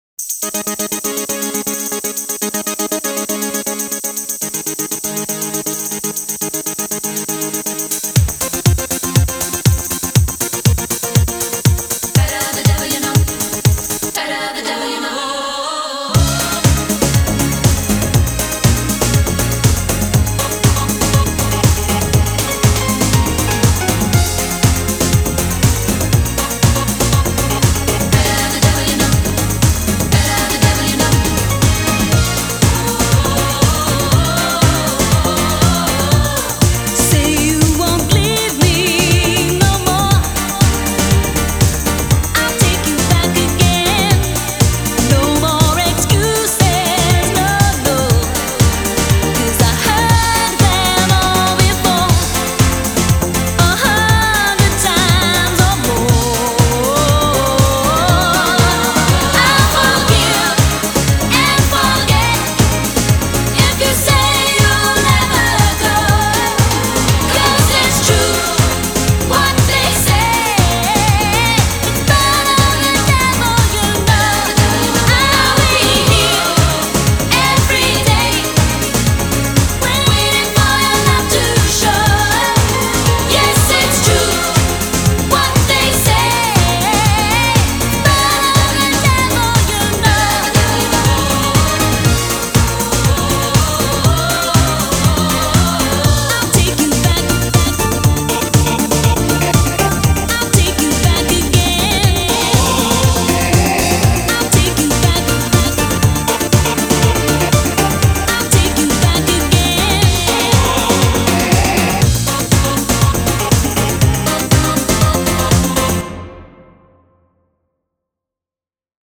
BPM120--1